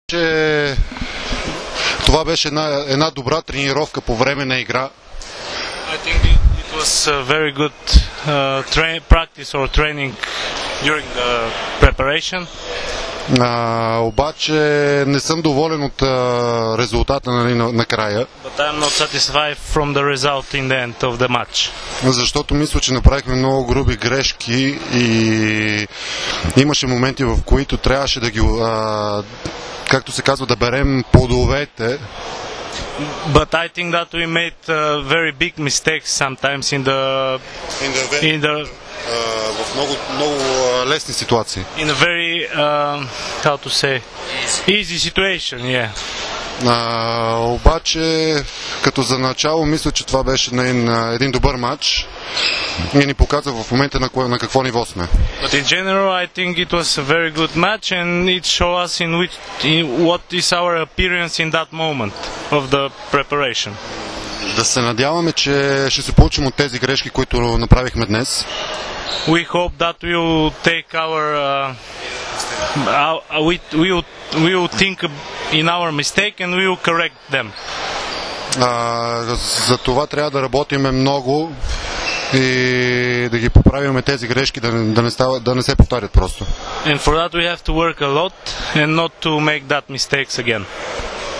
IZJAVA CVETANA SOKOLOVA